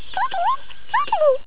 Rondoudou dit ... Rondoudou.